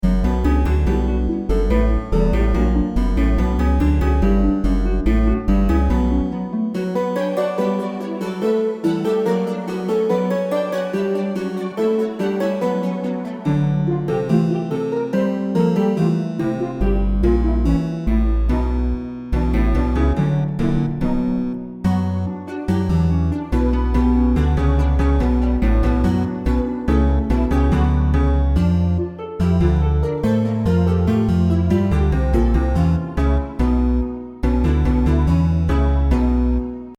Tune for the dusty cellars of a castle.